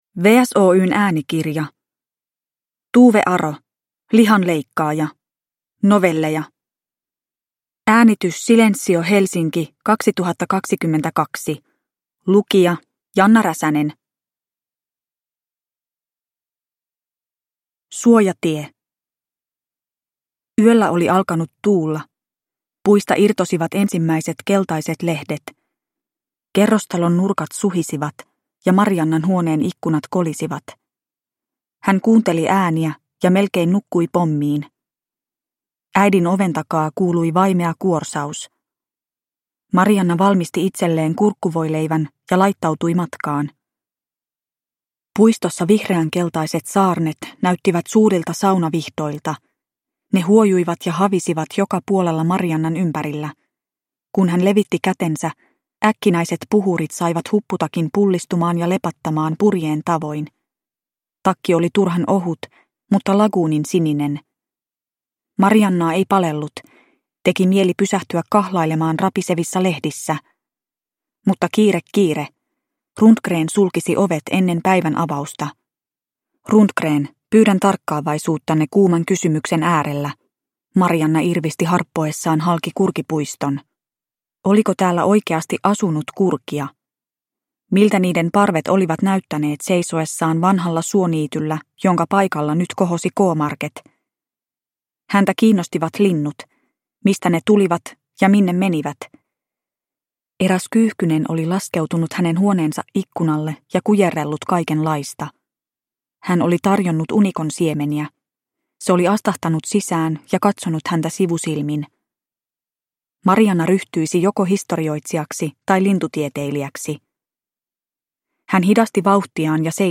Lihanleikkaaja – Ljudbok – Laddas ner